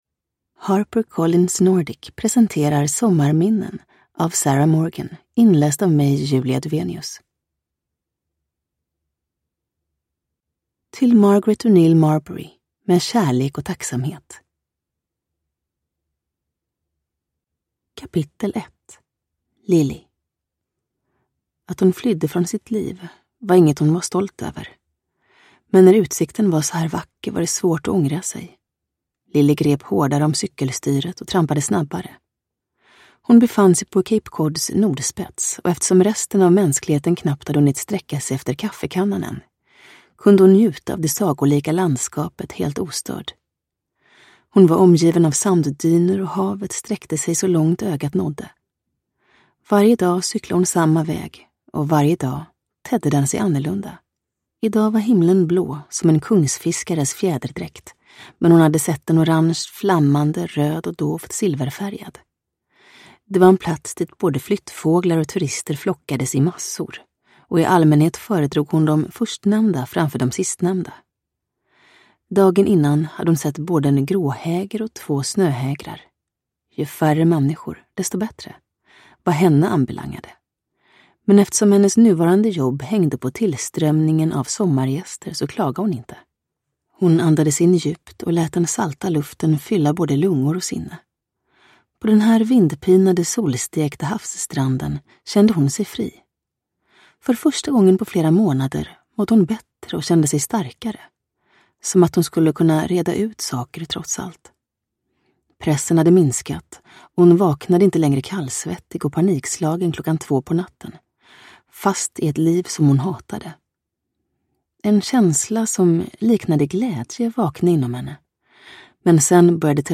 Uppläsare: Julia Dufvenius
Ljudbok